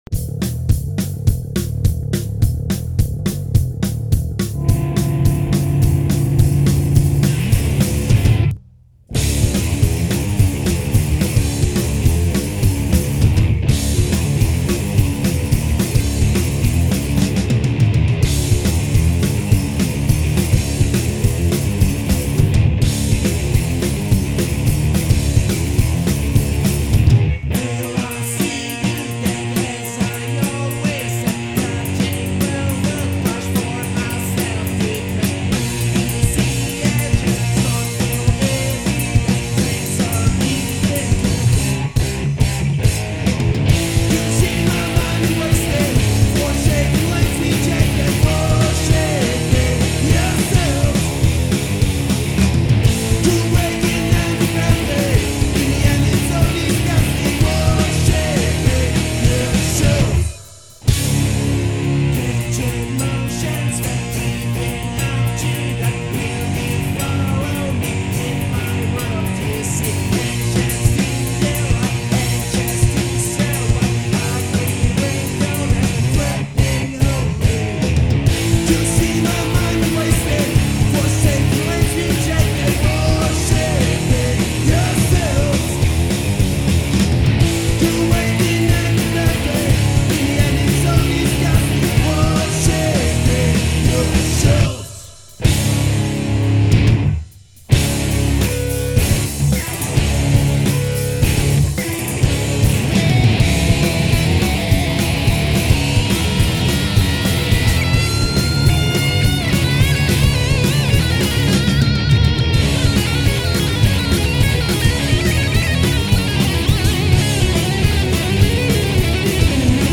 Aktuelle Proberaumdemo